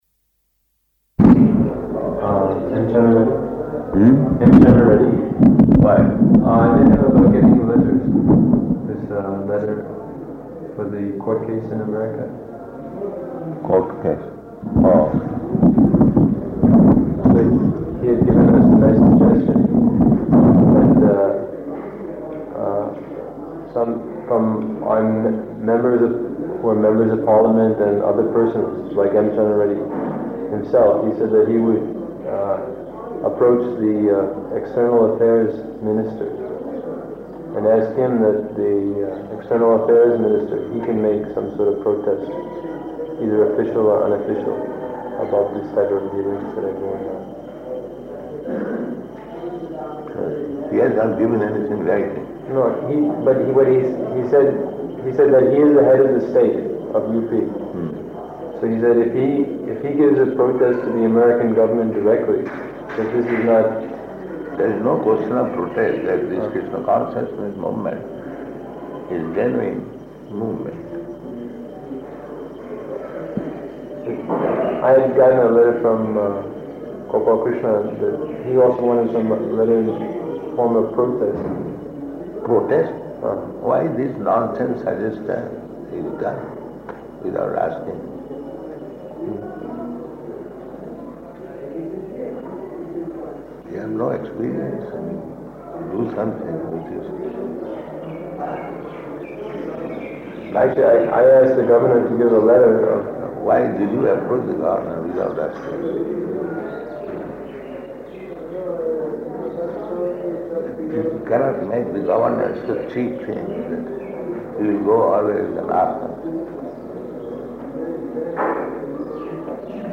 Room Conversation [partially recorded]
Location: Vṛndāvana